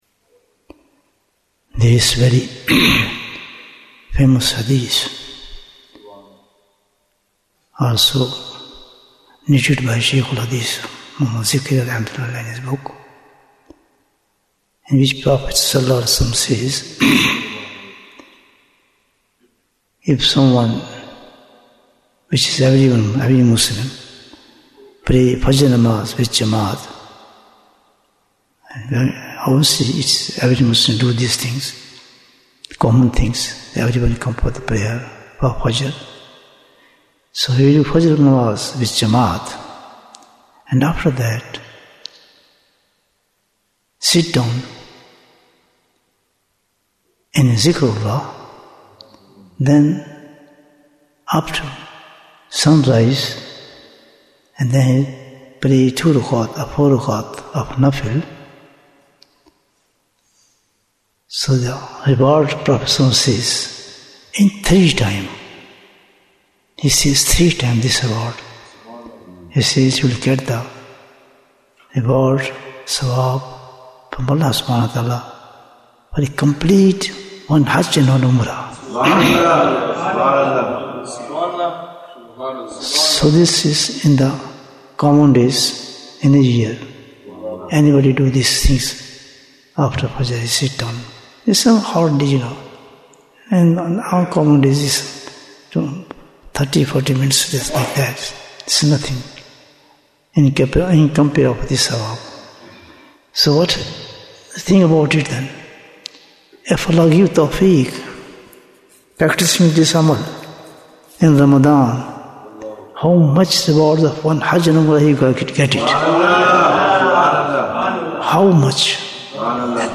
Jewels of Ramadhan 2026 - Episode 28 Bayan, 15 minutes11th March, 2026